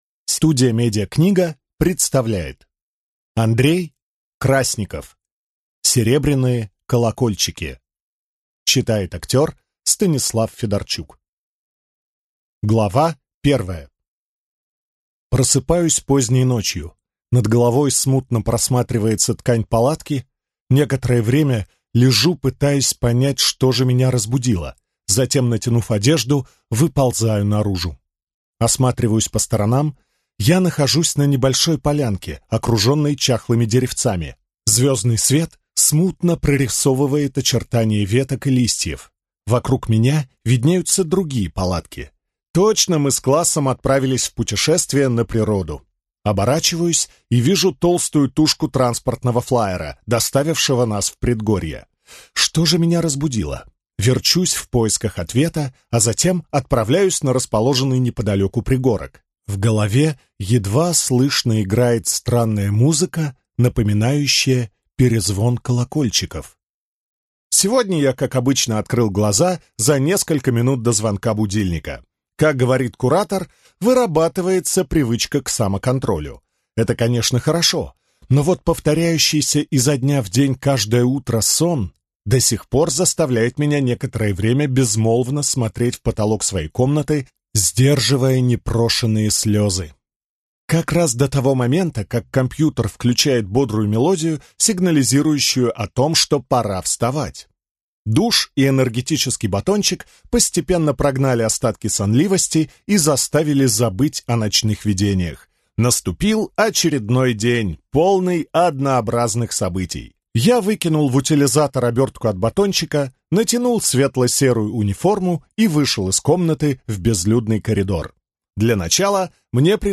Аудиокнига Серебряные колокольчики | Библиотека аудиокниг